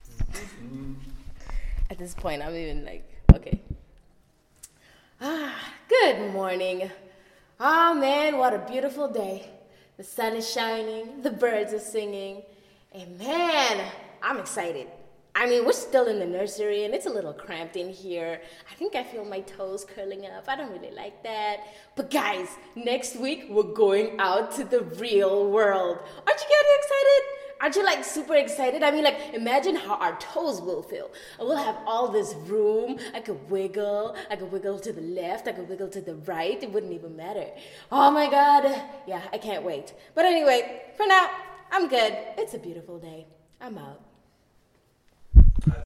VoiceOfABabyPlant.mp3